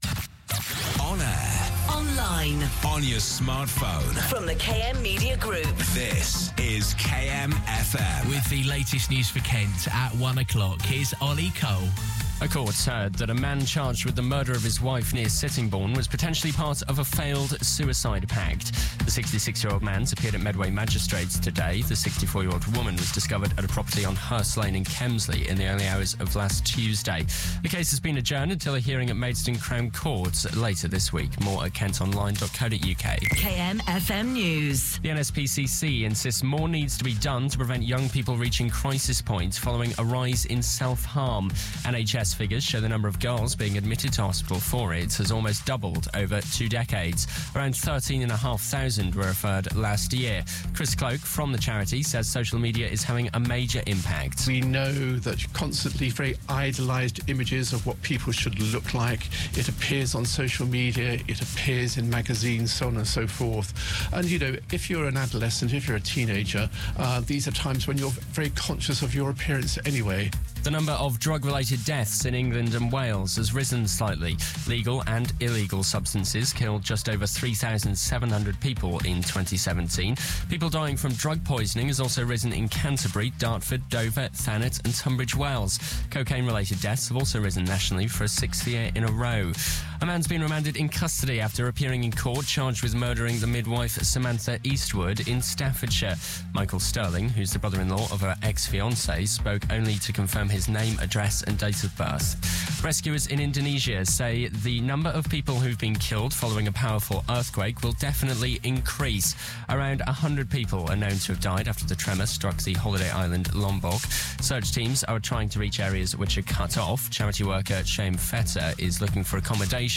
kmfm news